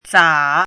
chinese-voice - 汉字语音库
za3.mp3